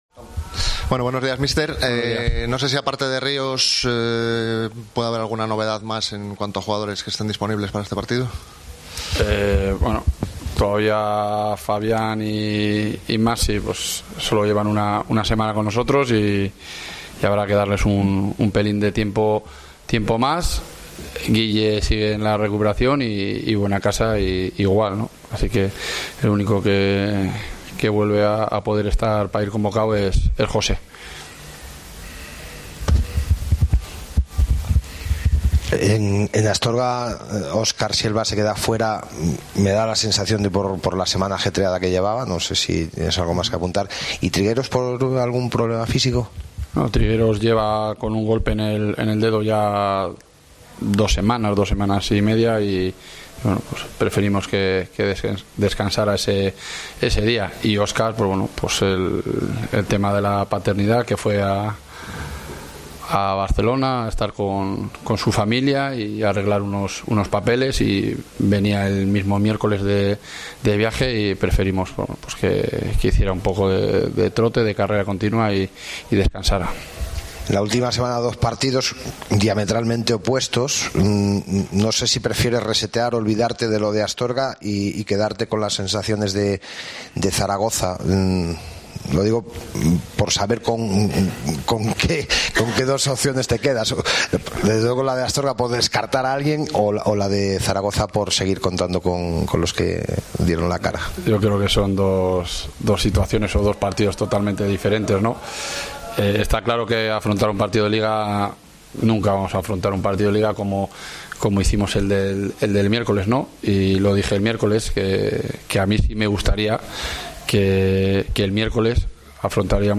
Escucha aquí las palabras del míster de la Deportiva Ponferradina antes del encuentro ante el CD Tenerife